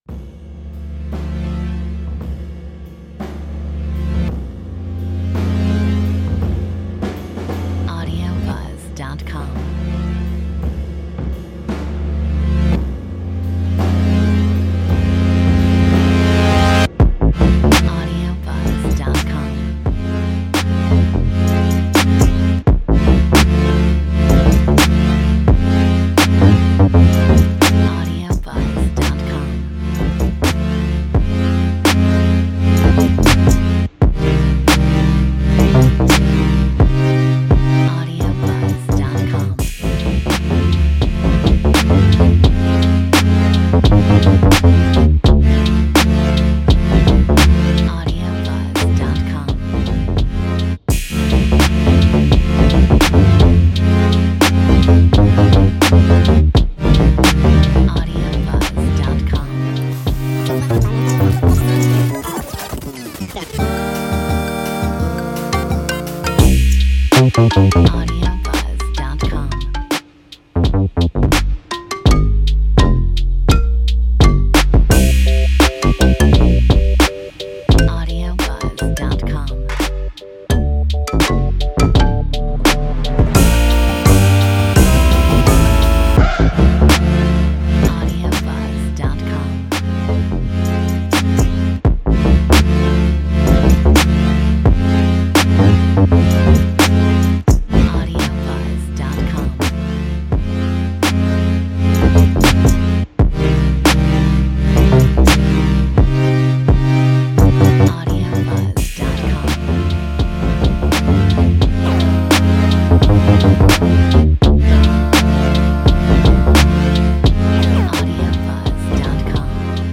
Metronome 82